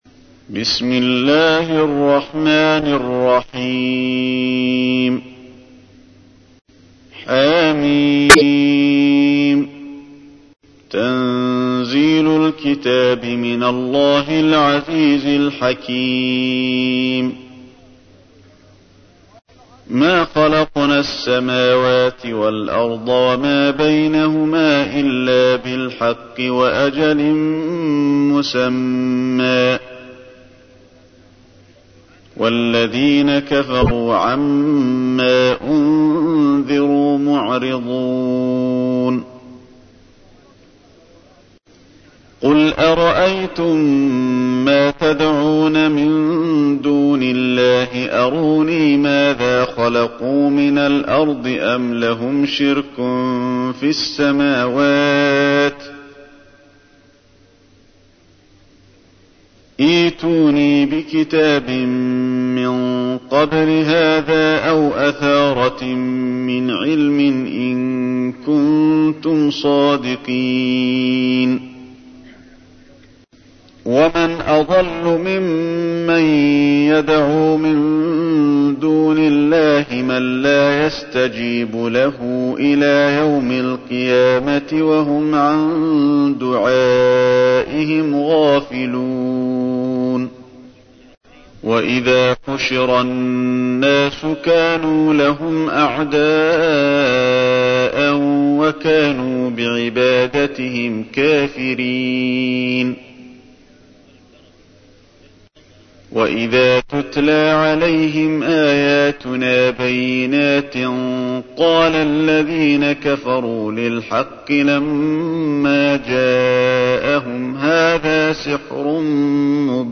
تحميل : 46. سورة الأحقاف / القارئ علي الحذيفي / القرآن الكريم / موقع يا حسين